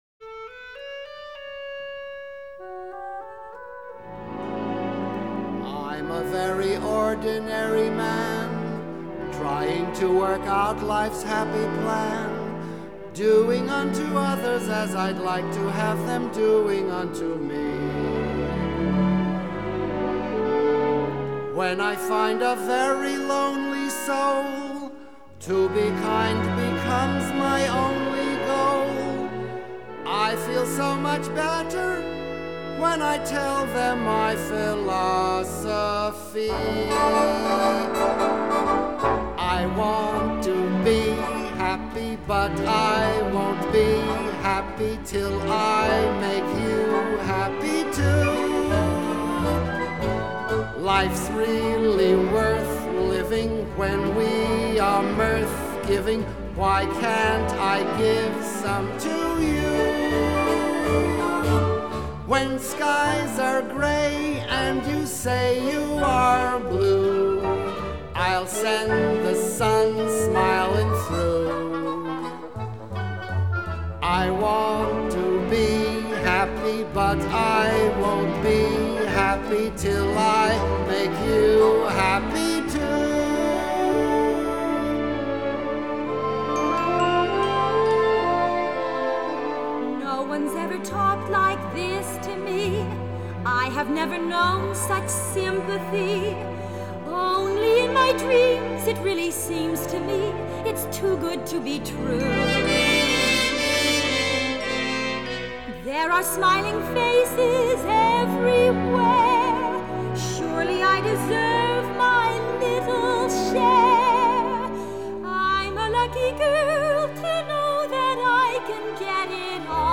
1925   Genre: Musical   Artist